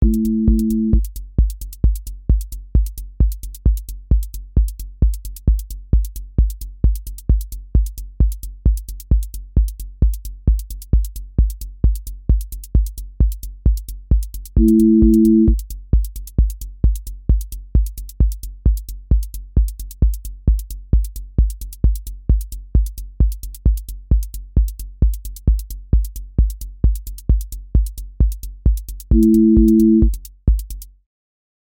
QA Listening Test techno Template: techno_hypnosis
hypnotic techno pressure loop with warehouse percussion, low-mid drive, and evolving texture motion
• voice_kick_808
• voice_hat_rimshot
• voice_sub_pulse